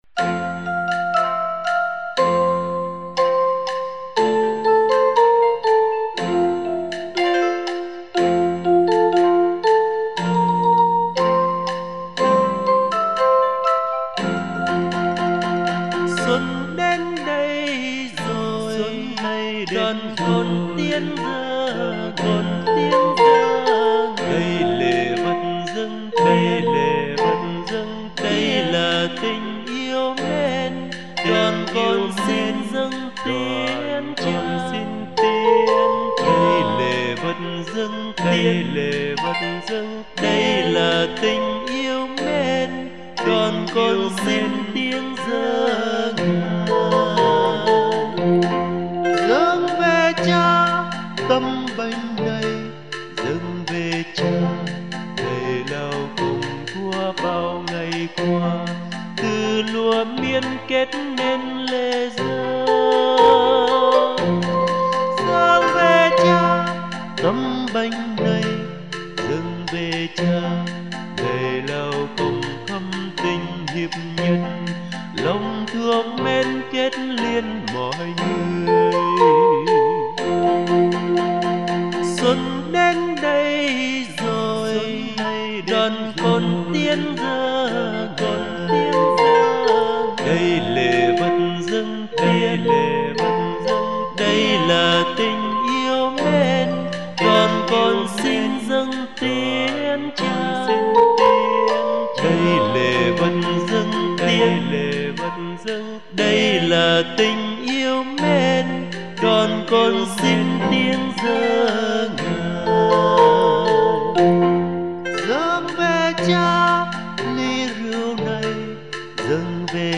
thuộc thể loại Nhạc thánh ca mùa Xuân.